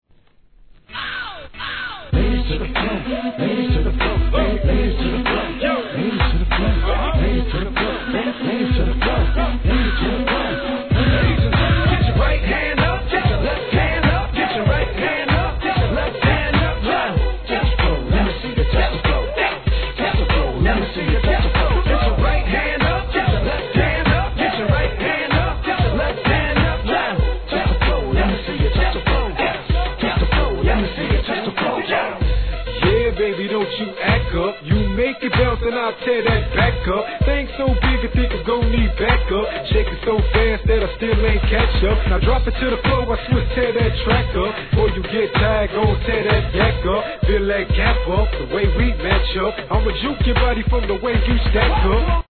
HIP HOP/R&B
らしいフロア・バンガー!